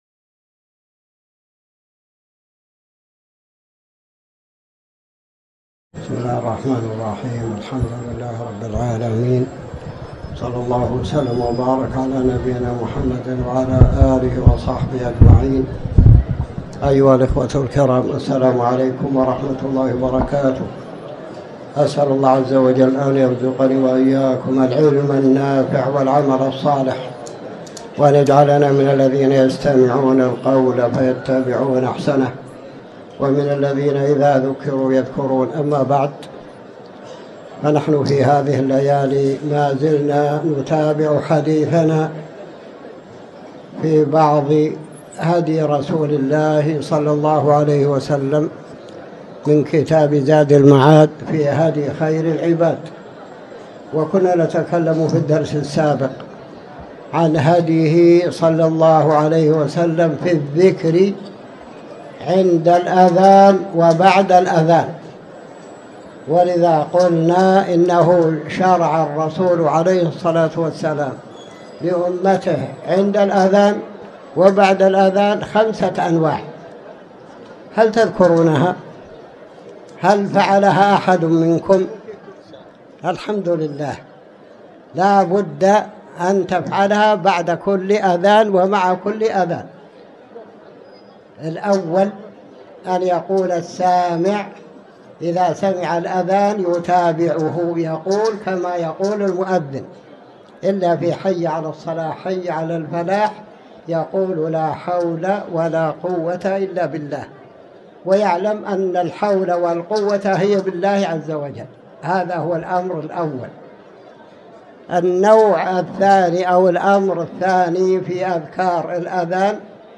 تاريخ النشر ٣٠ جمادى الأولى ١٤٤٠ هـ المكان: المسجد الحرام الشيخ